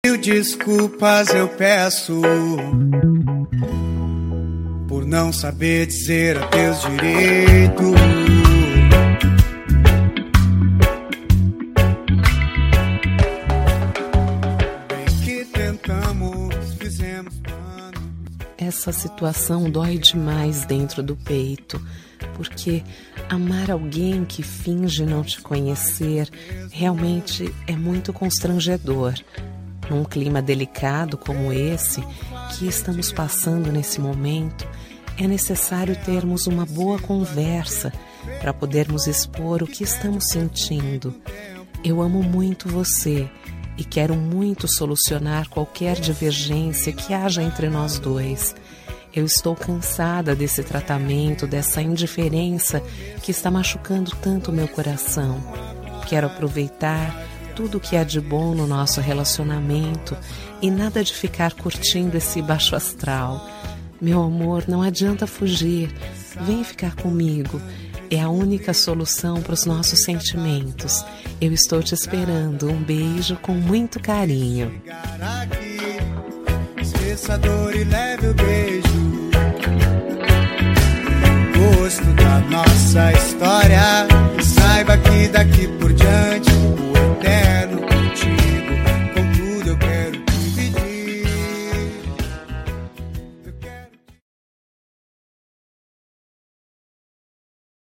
Telemensagem de Reconciliação – Voz Feminina – Cód: 035281